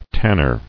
[tan·ner]